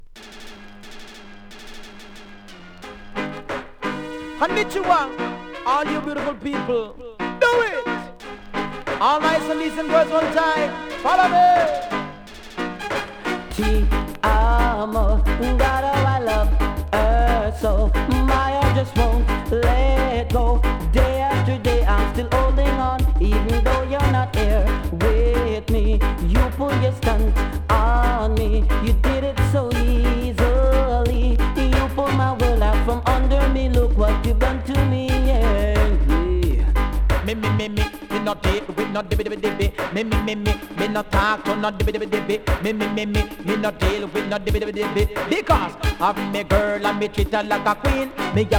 REGGAE 80'S
多少うすキズありますが音には影響せず良好です。